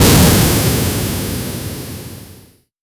flamethrower-with-aqua-no-jnvhwgil.wav